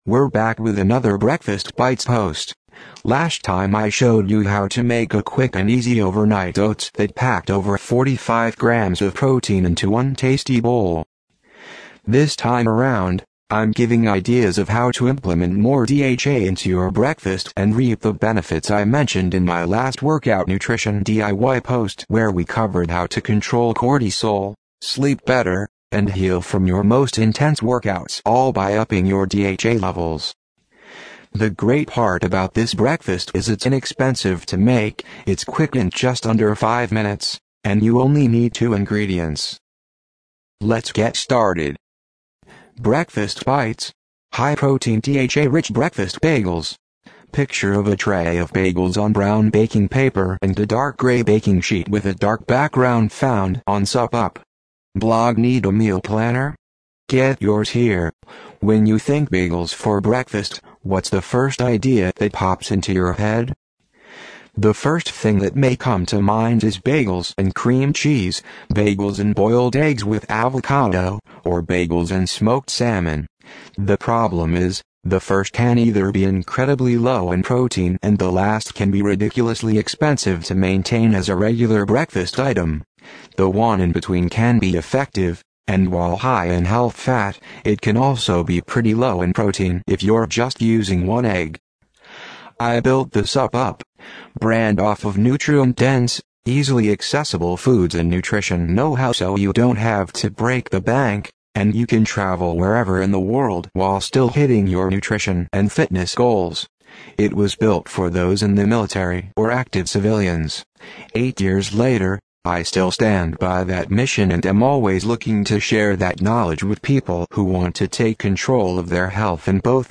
SUPP UP. TTS (for the vision impaired):